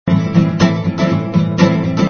misc_spanishGuit00.mp3